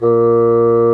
Index of /90_sSampleCDs/Roland L-CDX-03 Disk 1/WND_Bassoons/WND_Bassoon 4
WND CSSN A#2.wav